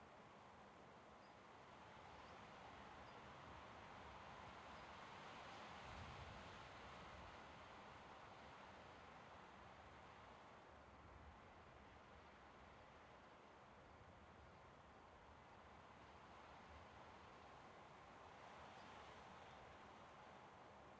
sfx_amb_map_zoomedout_hills.ogg